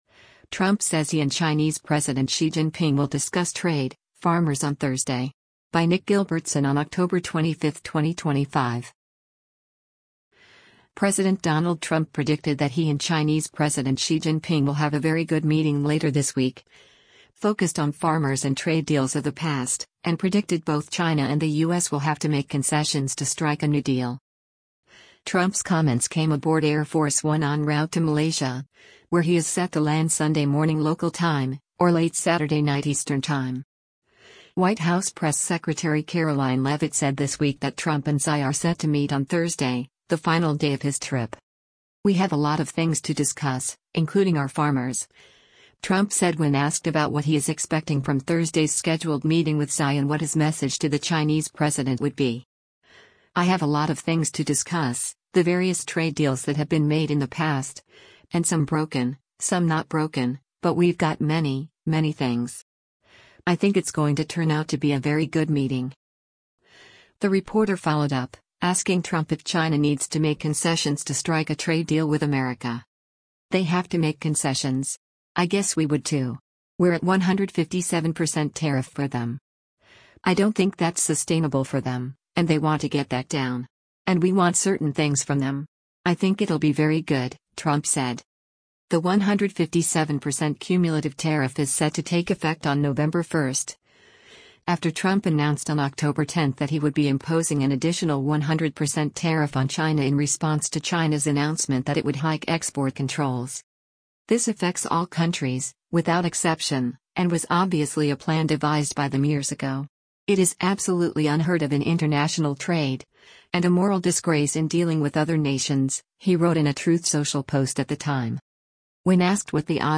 Trump’s comments came aboard Air Force One en route to Malaysia, where he is set to land Sunday morning local time, or late Saturday night Eastern time.
The reporter followed up, asking Trump if China needs to make concessions to strike a trade deal with America.